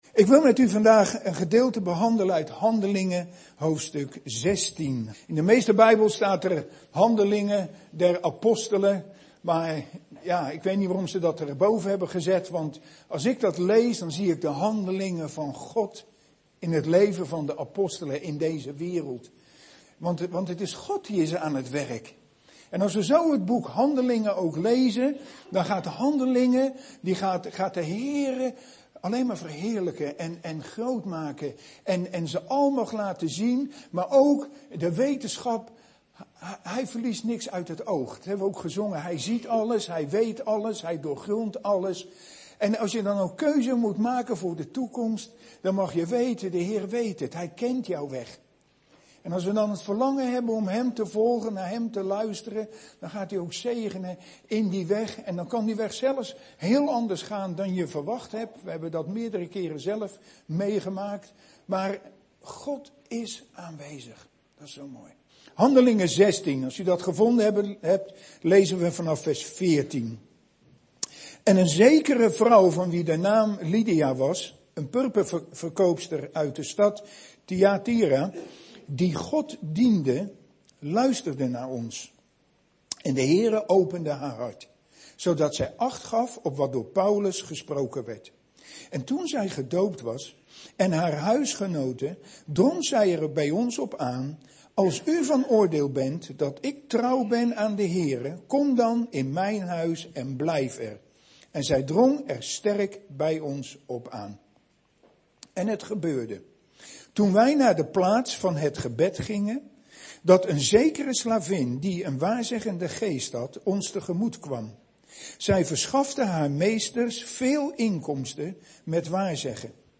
Een preek over 'De bijbel: een blok aan het been?'.